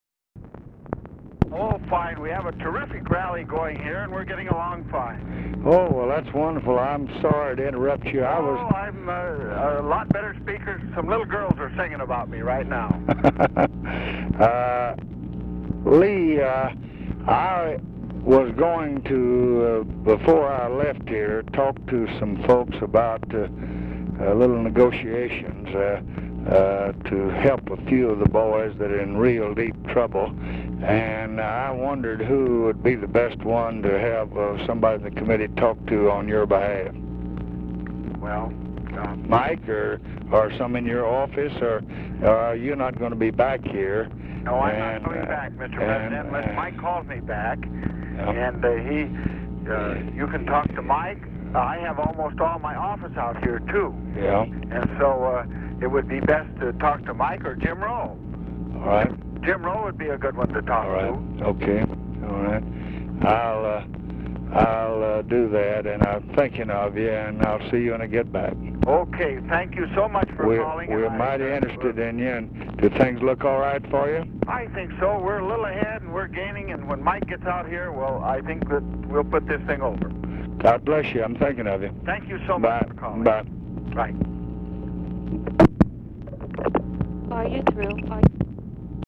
RECORDING STARTS AFTER CONVERSATION HAS BEGUN
Format Dictation belt
Specific Item Type Telephone conversation Subject Congressional Relations Elections National Politics